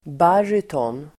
Ladda ner uttalet
Uttal: [b'ar:ytån]